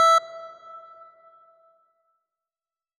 E2.wav